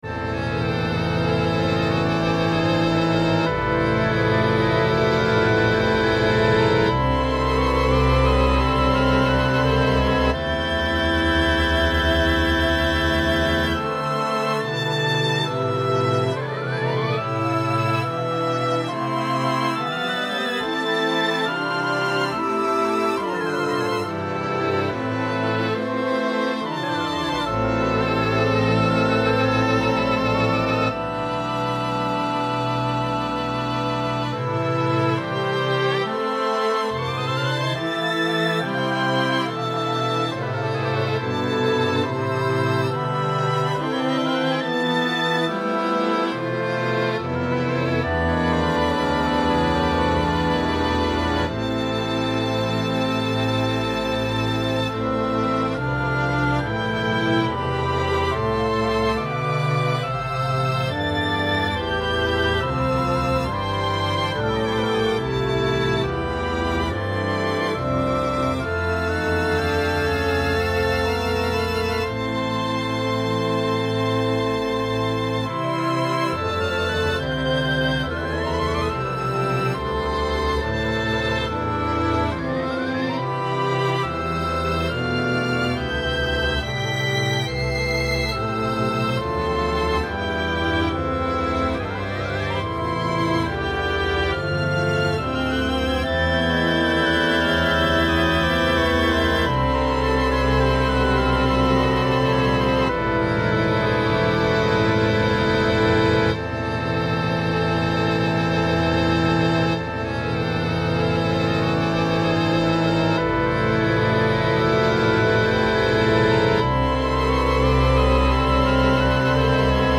But I also do songs in other genres, where this is the sketch for a Classical song about swirling snow, which at present is a few strings, woodwinds, and brass .